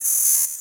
sci-fi_code_fail_04.wav